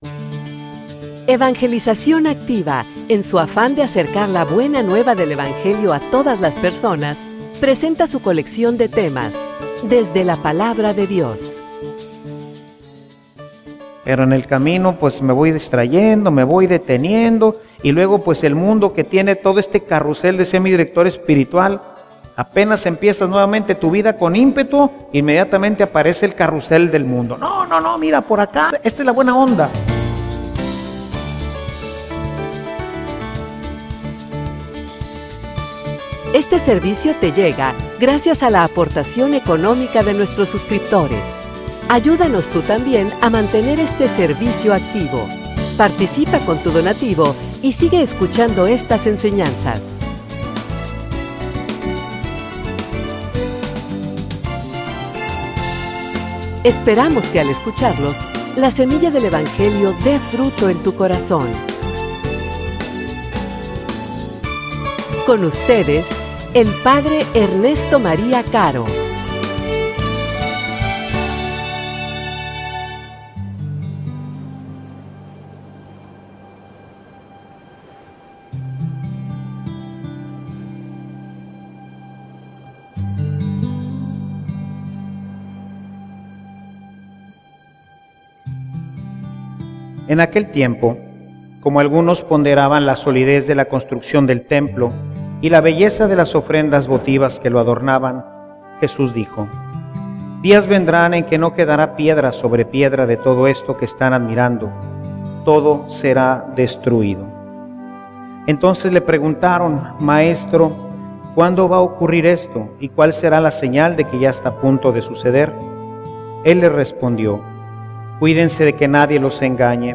homilia_No_le_aflojes.mp3